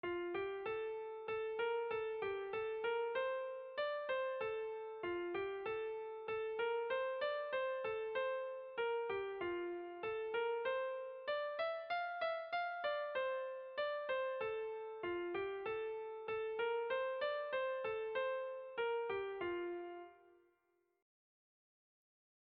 Irrizkoa
Zortziko txikia (hg) / Lau puntuko txikia (ip)
A1A2BA2